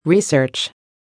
1. Now look at the words and learn how to pronounce them.
research.mp3